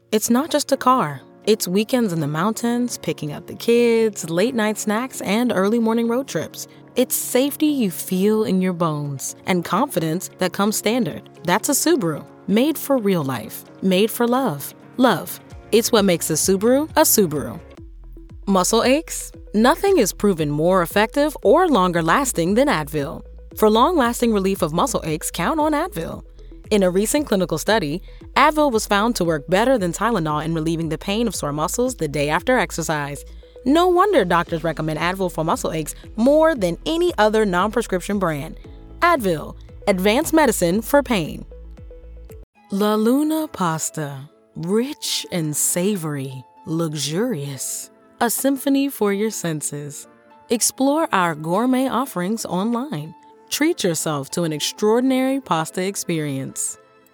If you’re looking for a voice that brings heart, humor, and hustle—I’m your girl.
Commercial Demo
English - Southern U.S. English
Young Adult